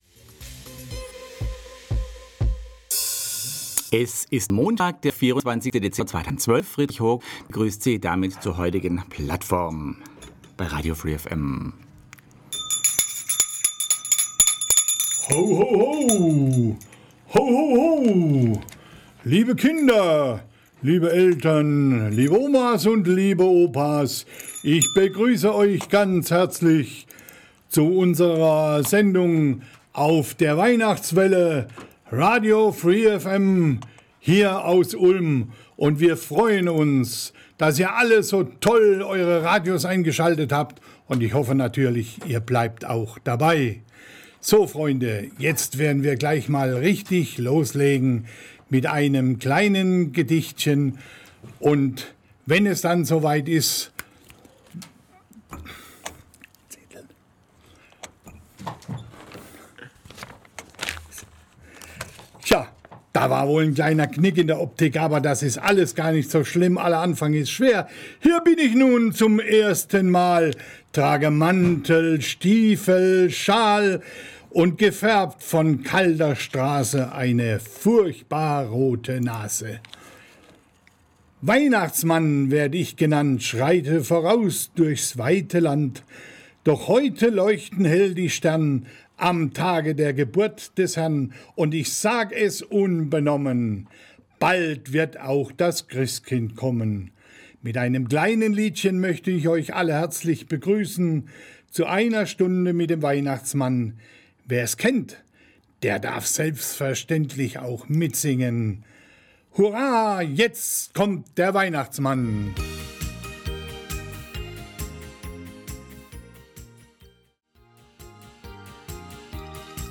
Von den Freuden und Leiden des Santa Claus berichtet eben derselbe persönlich und live im Radio free FM Studio in Ulm an Heilig Abend von 16 bis 17 Uhr in der Plattform.